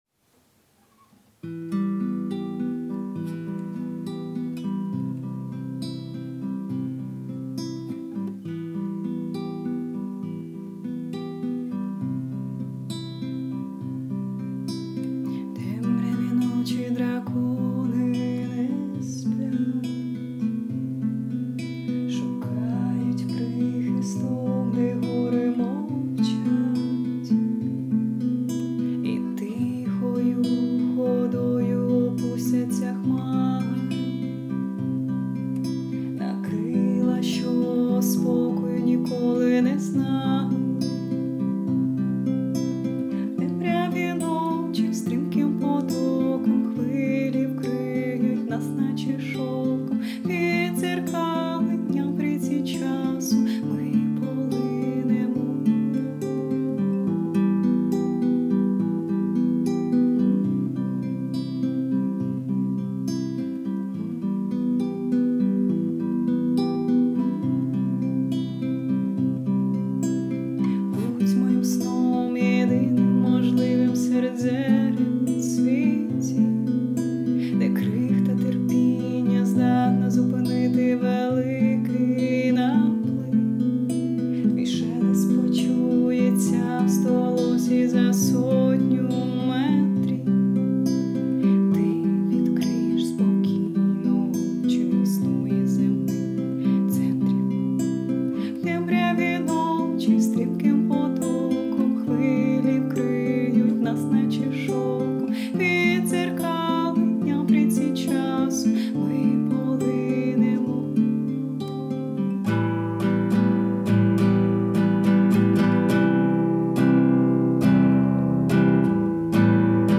ТИП: Музика
СТИЛЬОВІ ЖАНРИ: Ліричний